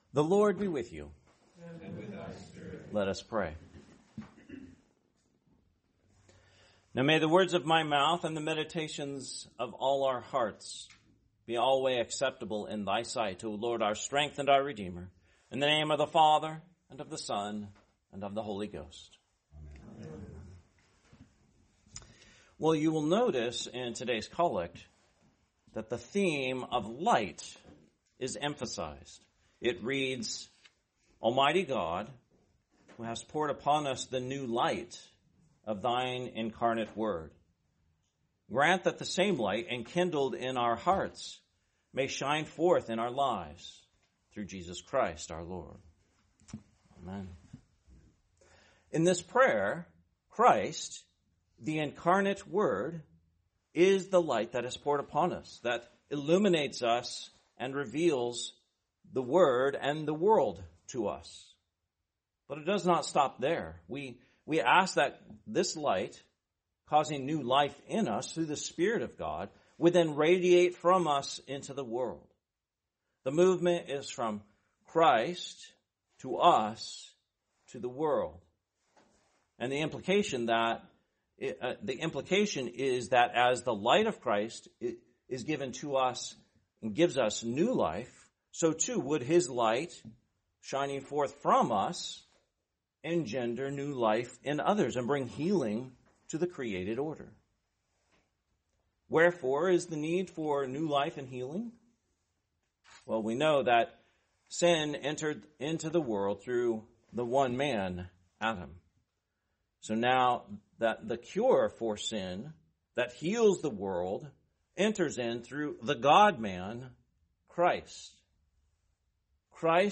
Sermon, The 2nd Sunday after Christmas, 2026